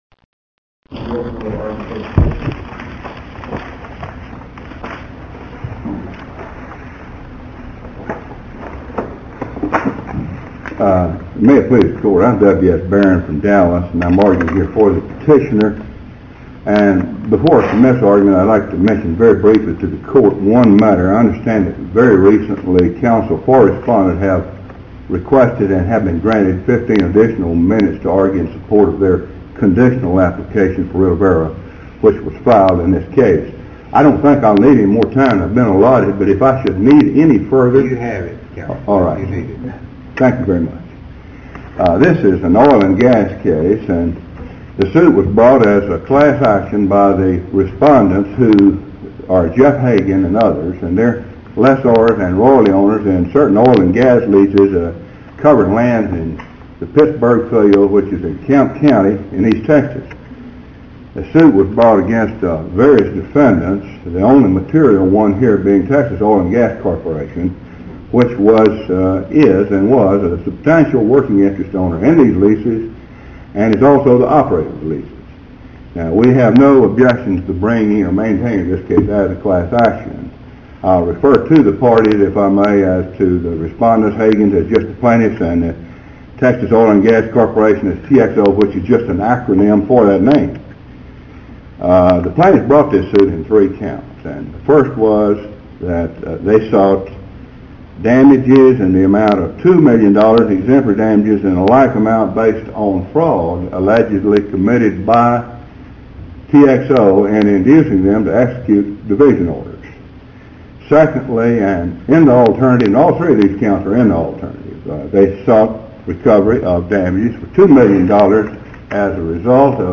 Case No. C-3768 Oral Arguments Audio (MP3)